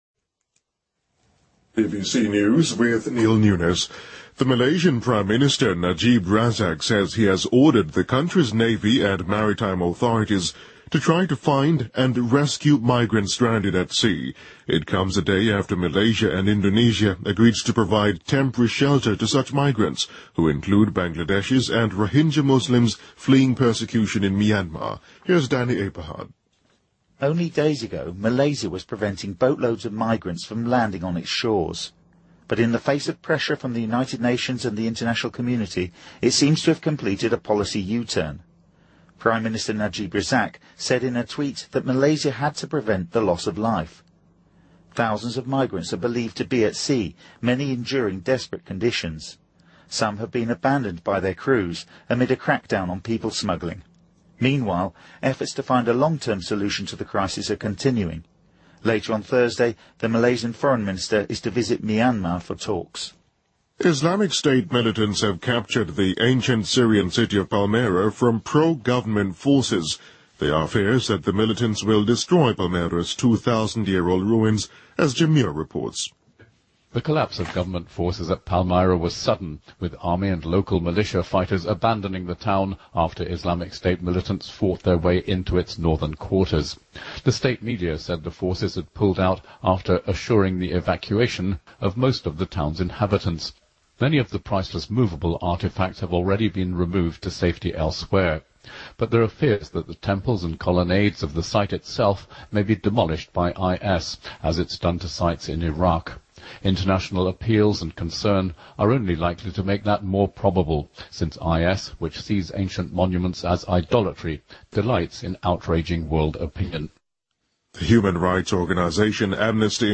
BBC news,马来西亚政府拯救被困海上移民
日期:2015-05-23来源:BBC新闻听力 编辑:给力英语BBC频道